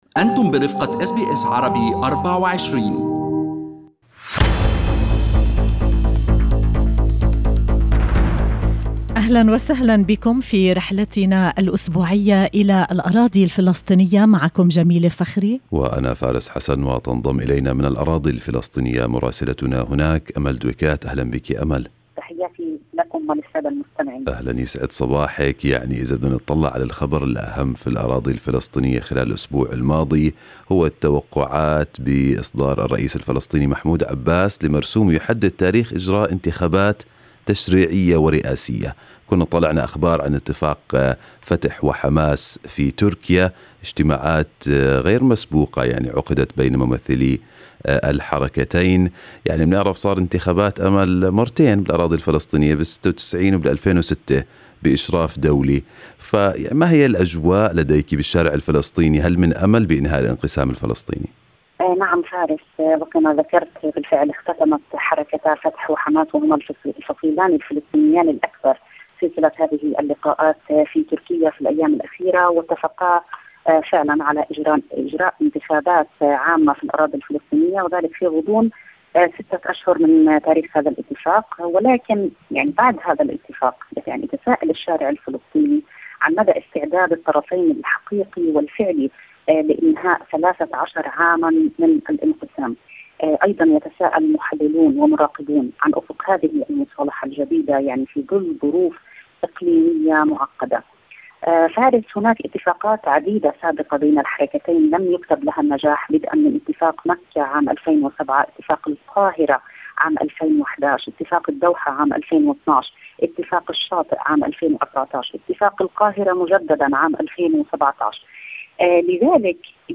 من مراسلينا: أخبار الأراضي الفلسطينية في أسبوع 28/9/2020